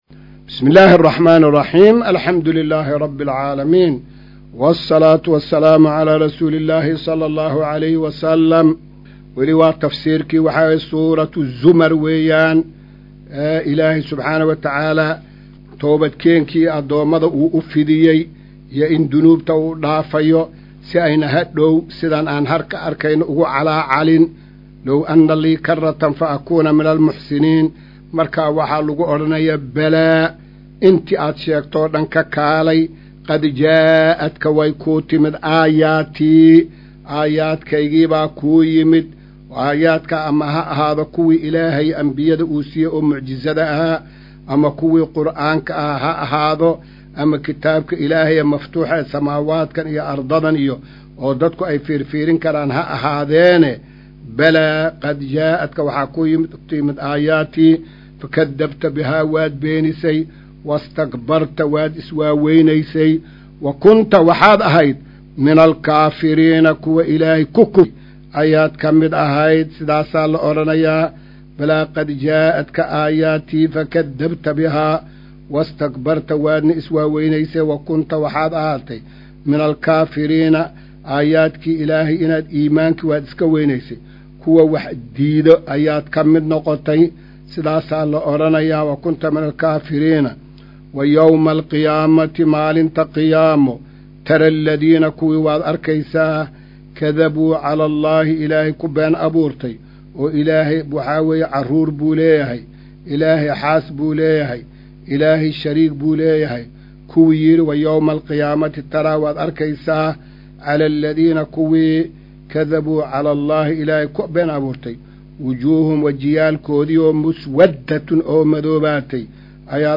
Maqal:- Casharka Tafsiirka Qur’aanka Idaacadda Himilo “Darsiga 221aad”